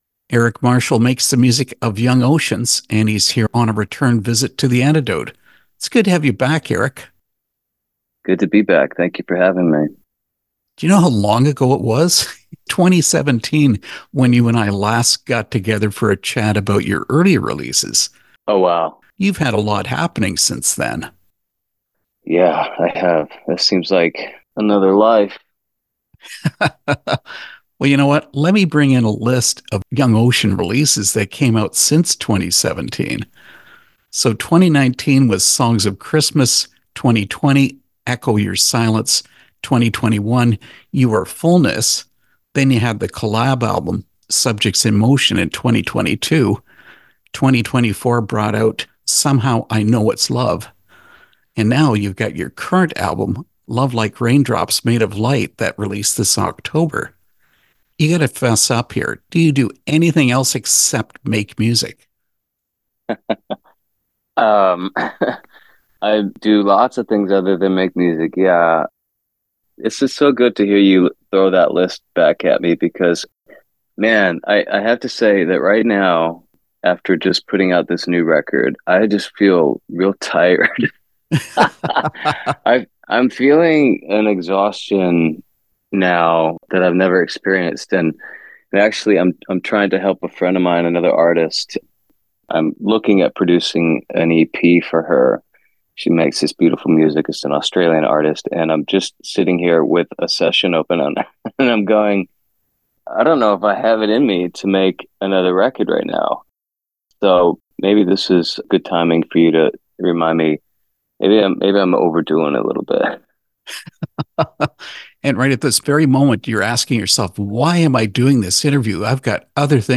Interview with Young Oceans (2025)